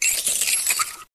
KLEFKI.wav